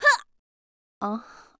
fishing_fail_v.wav